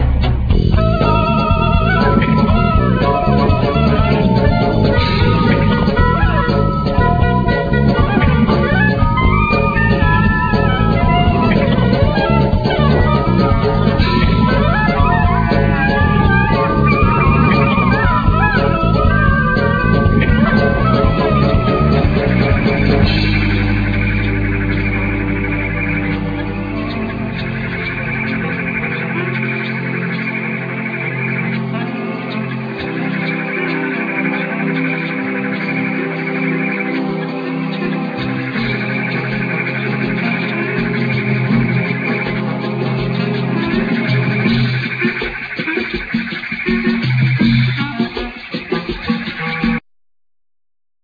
Bass-gitar
Drums,Percussions
Voice,Sampler,Djembe
Clarinet
Mong guitar